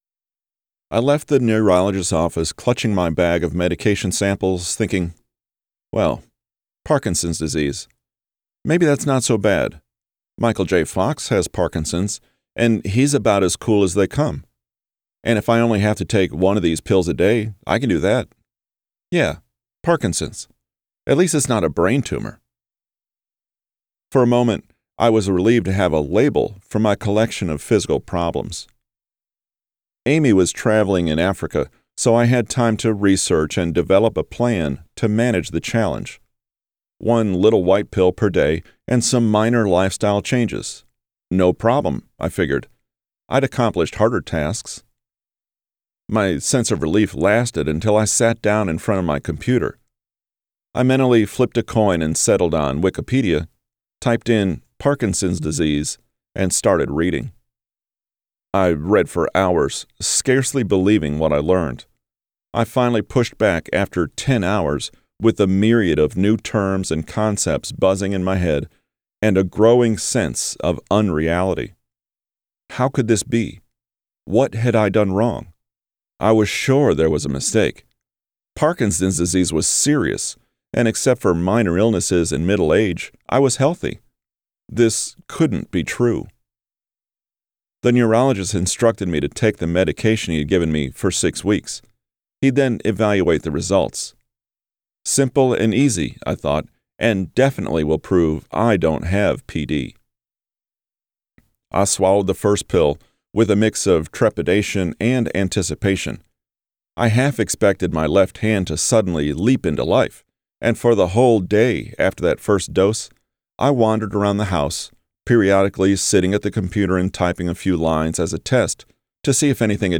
Walking With Parkinson audiobook available soon
Categories: Audiobook, Production NewsTags: , ,